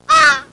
Crow Call Sound Effect
Download a high-quality crow call sound effect.
crow-call.mp3